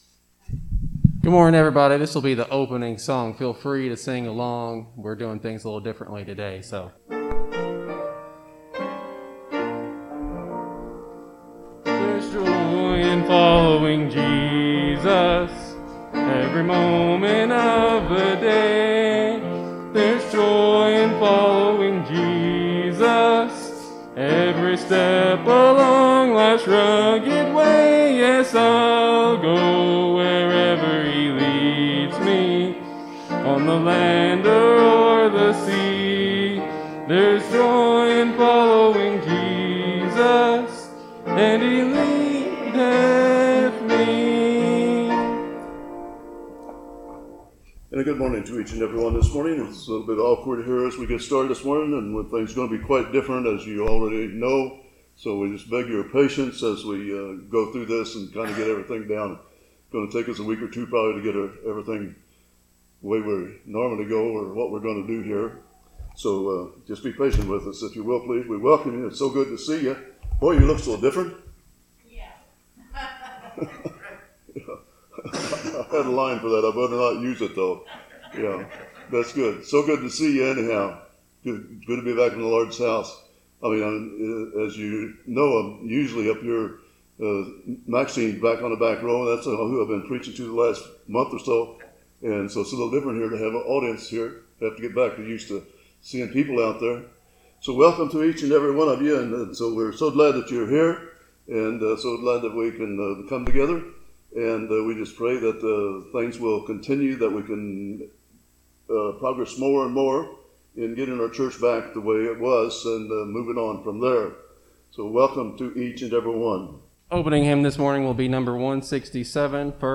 Sermons - Porter Christian Church
Sunday Morning Worship Service - Where do we go from here?